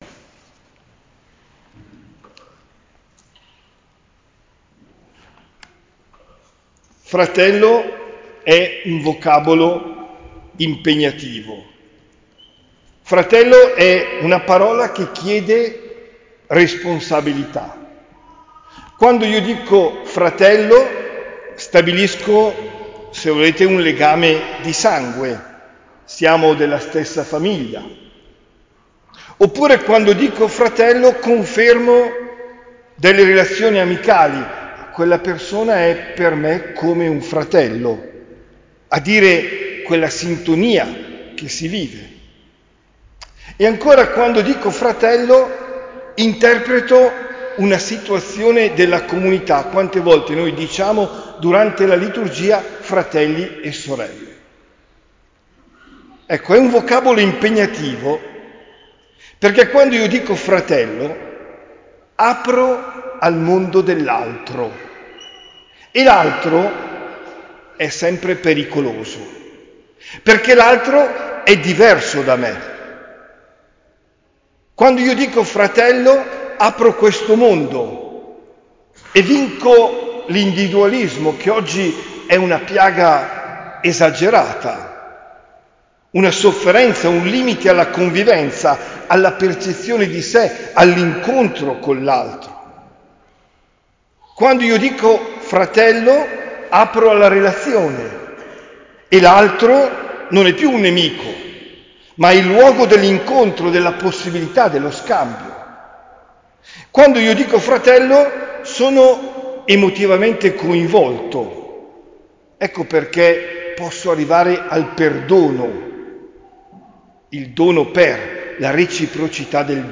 OMELIA DEL 10 SETTEMBRE 2023